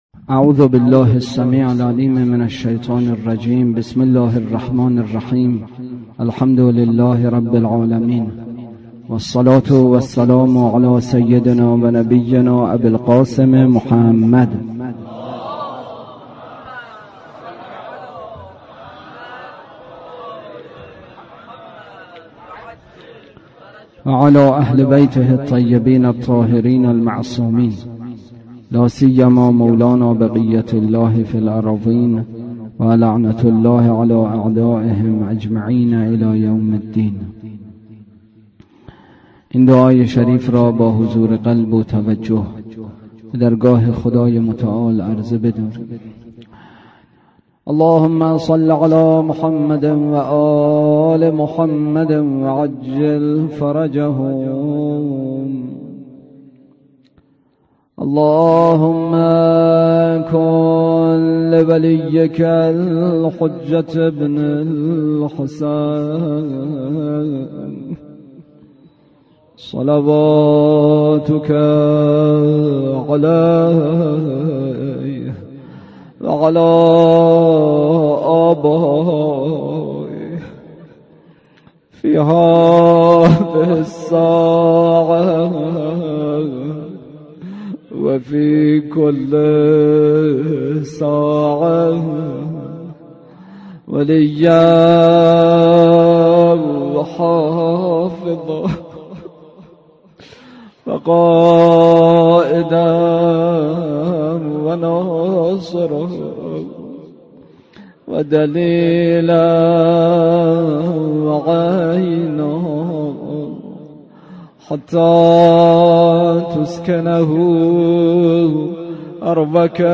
روضه: روضه‌ی قمر بنی‌هاشم(ع)